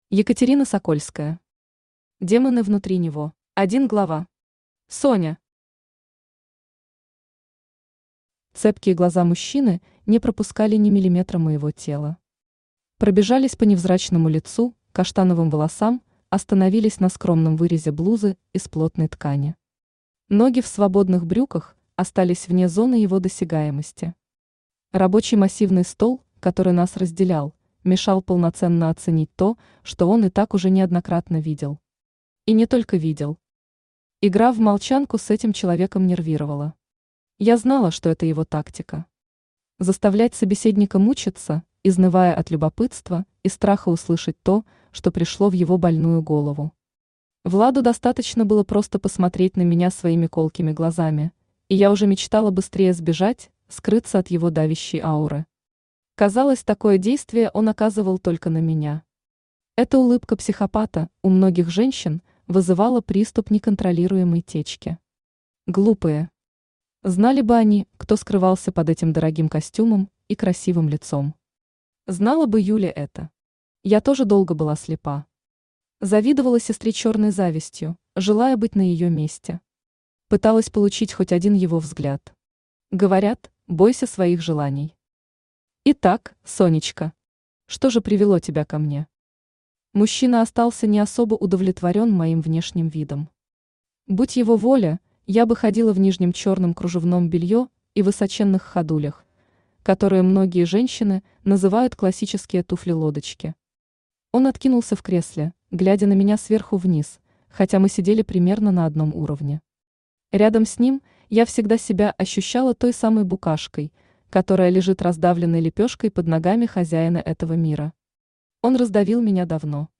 Аудиокнига Демоны внутри него | Библиотека аудиокниг